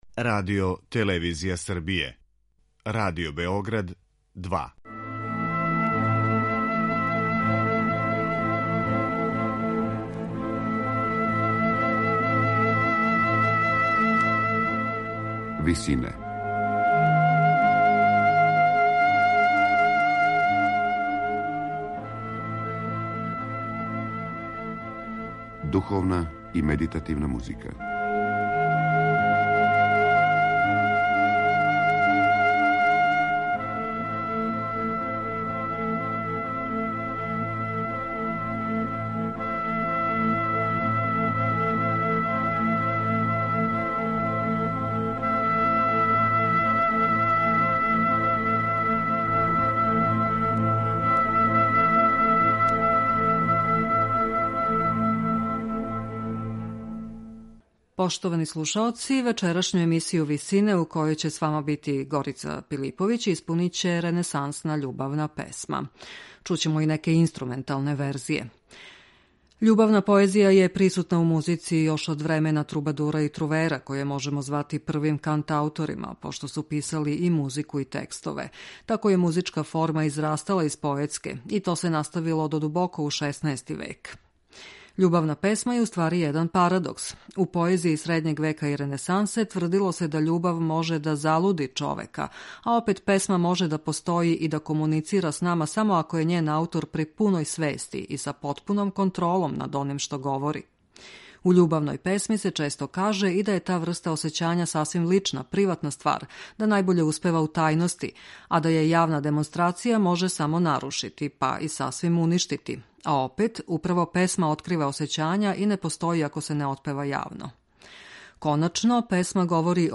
испуниће ренесансне љубавне песме разних аутора.